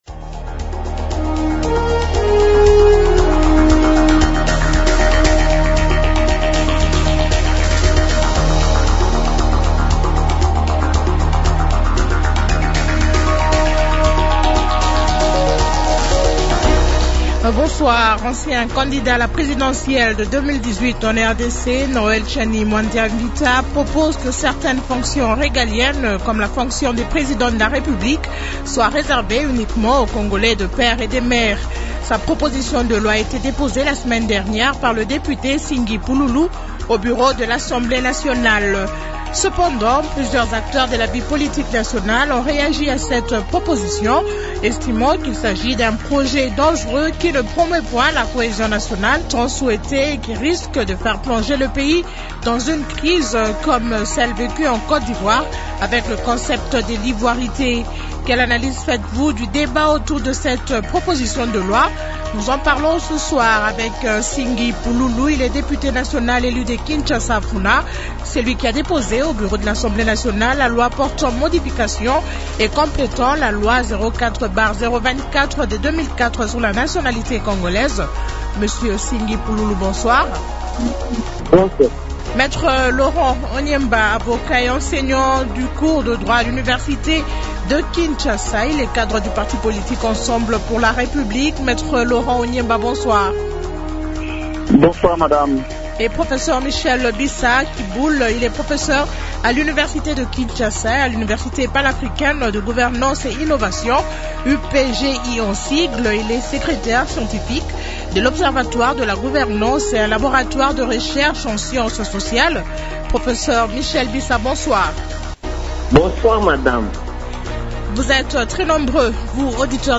Invités : -N’singi Pululu, député national élu de Kinshasa-Funa.